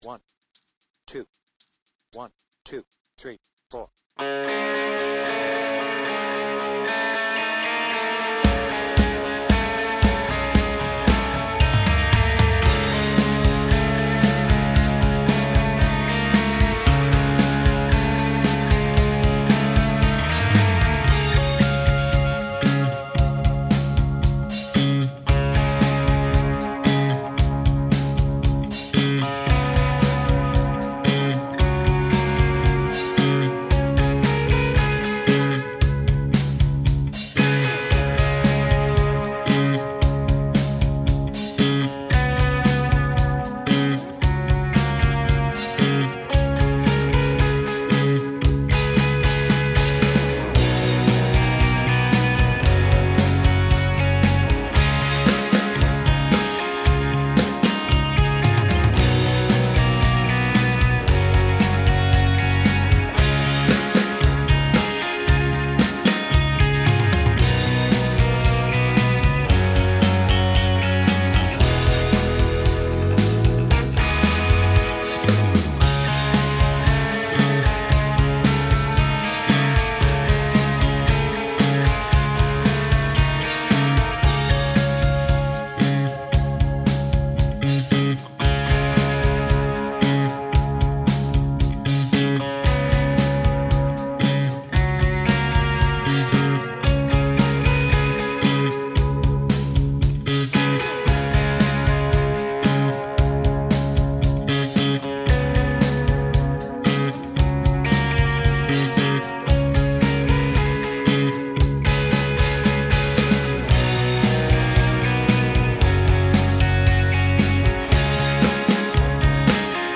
Without vocals